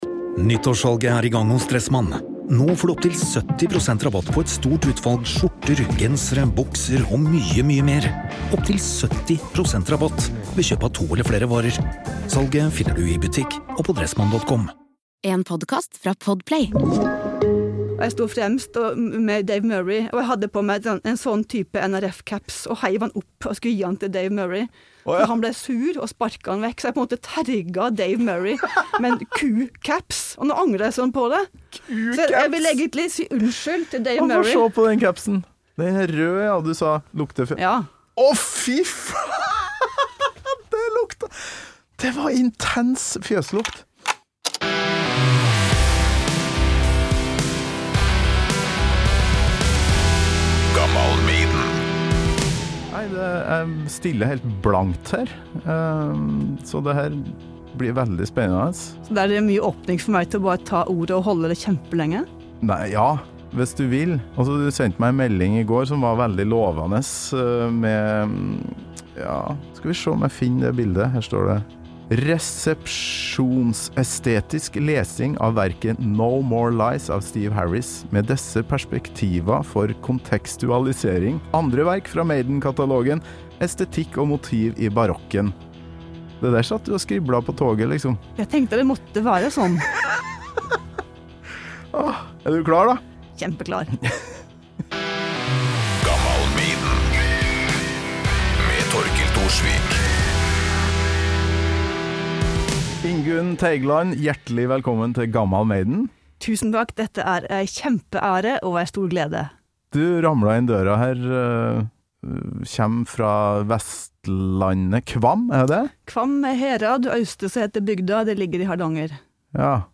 Fjøslukta sitter godt i Gammal Maiden-studioveggene fremdeles!